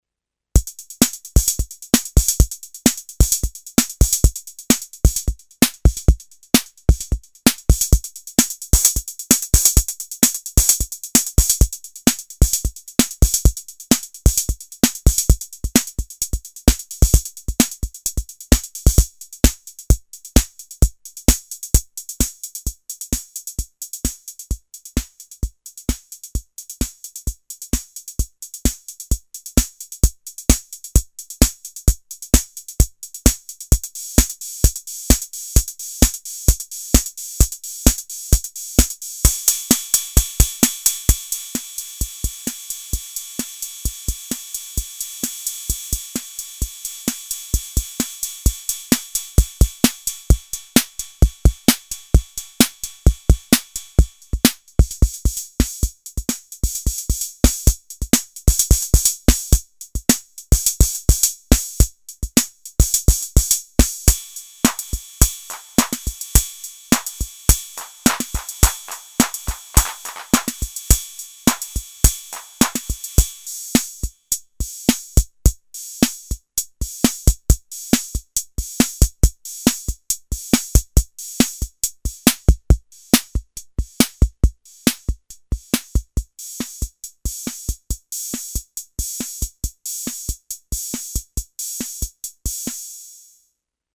The DR sound reminds closely the Roland TR-606 a bit metallic and compressed but pretty good.
- mono out
demoAUDIO DEMO
pattern demo
- analog drum